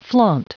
Prononciation du mot flaunt en anglais (fichier audio)
Prononciation du mot : flaunt